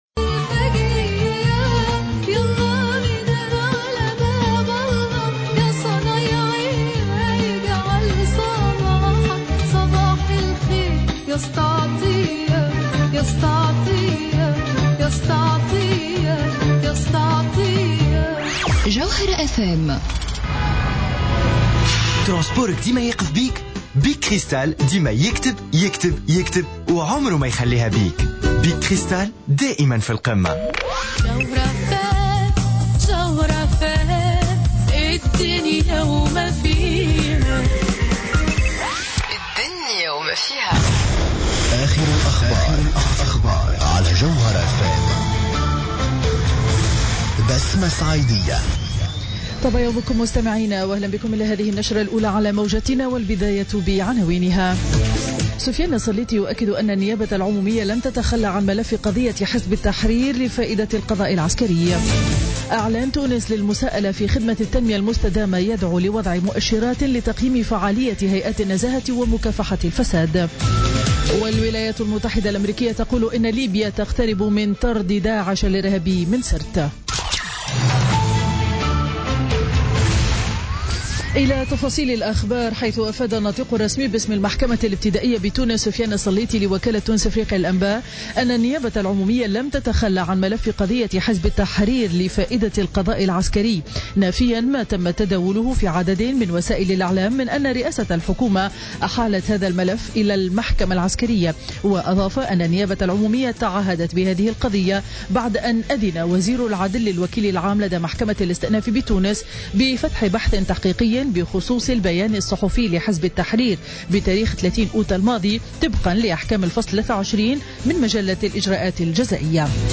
نشرة أخبار السابعة صباحا ليوم الخميس 8 سبتمبر 2016